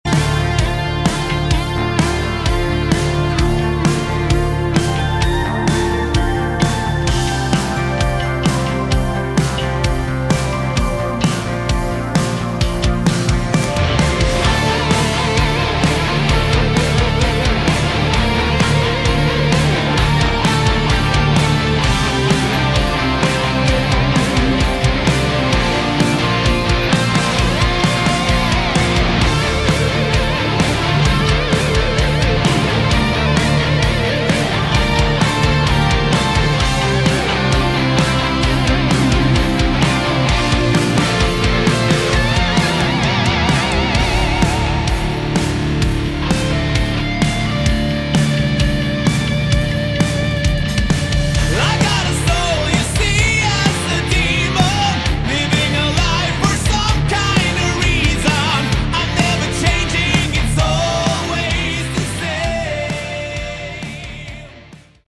Category: Melodic Metal
rhythm, lead and acoustic guitars
drums and percussion
vocals
bass guitar
A great harder edged melodic rock.